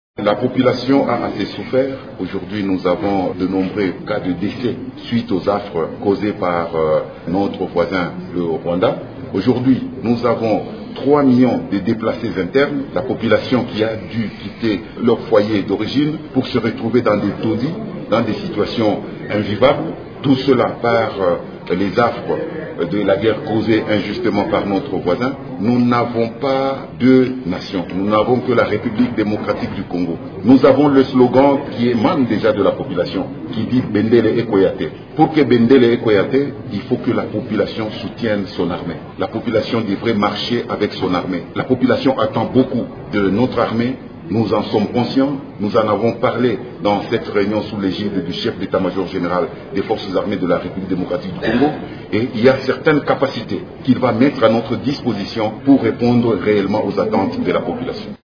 Il a par ailleurs invité la population à soutenir et collaborer avec son armée pour faire face à l’ennemi :